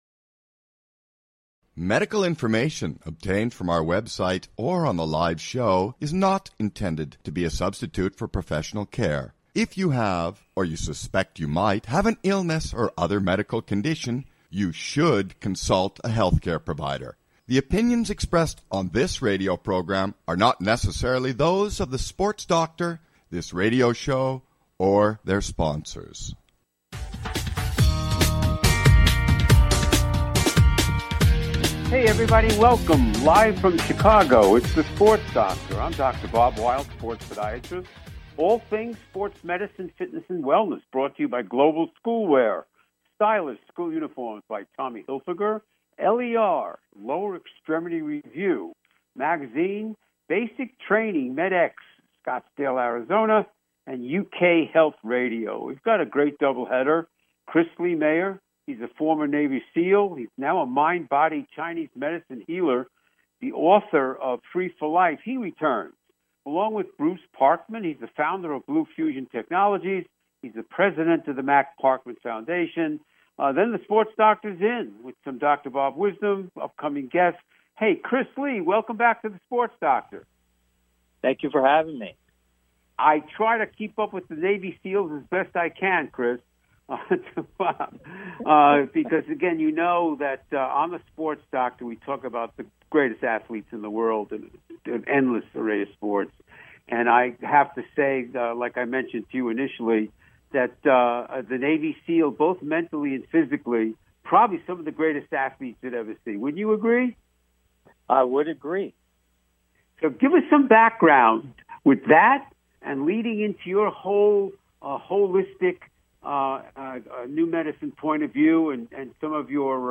Talk Show Episode, Audio Podcast, The Sports Doctor and Guests